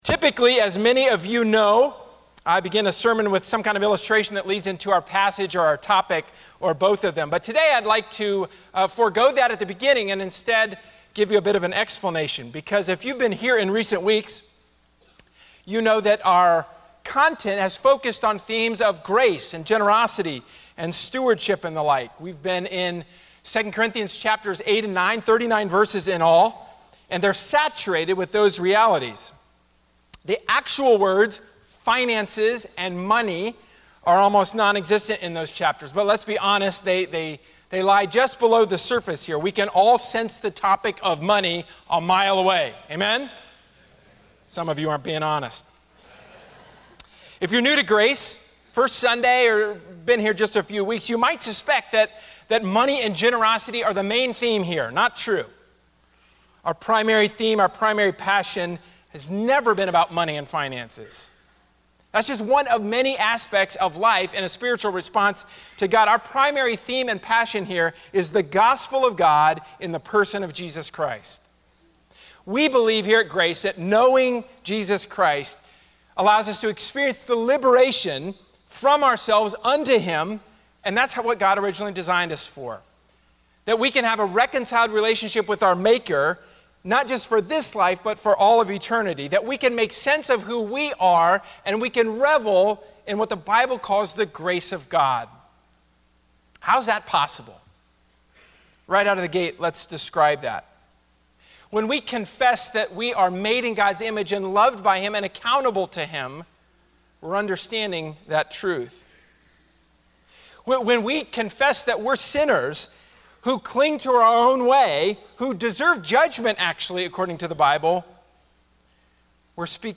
A sermon from the series "Transparent."